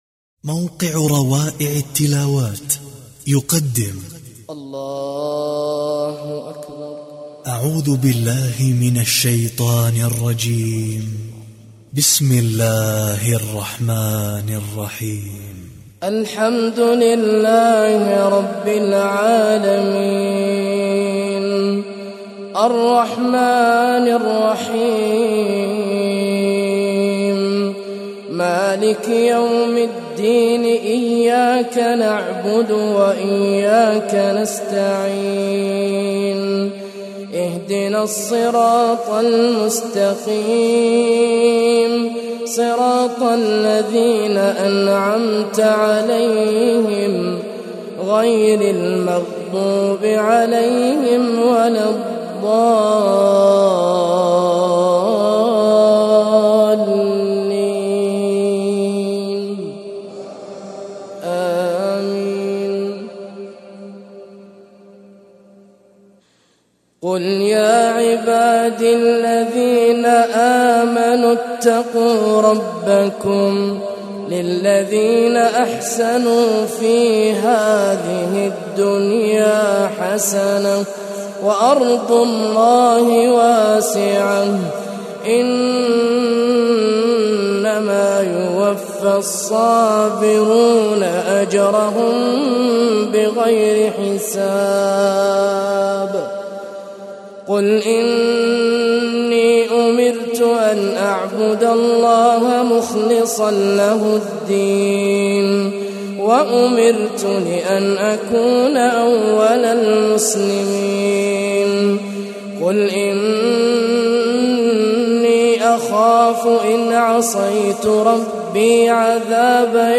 الرئيسية تلاوات خاشعة ما تيسر من سورة الزمر